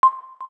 KEYTONE1_8.wav